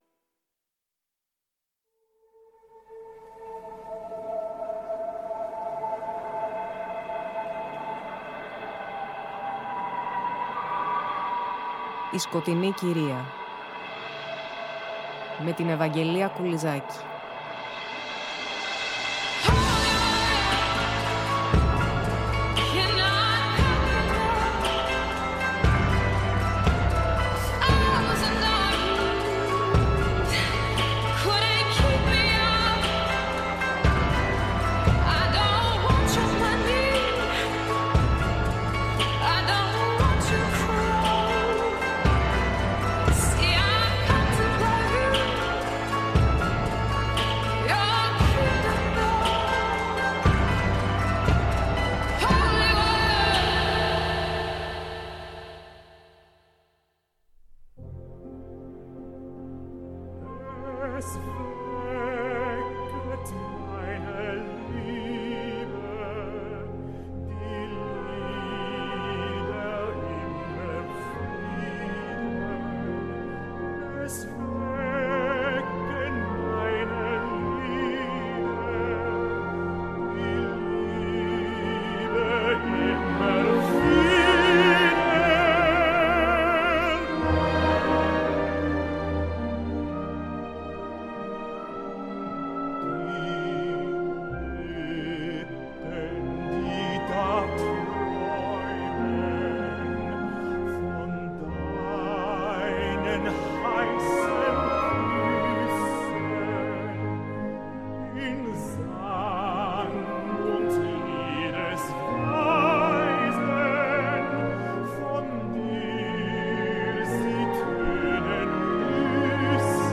Η εκπομπή πλαισιώνεται με συνθέσεις και τραγούδια των δύο.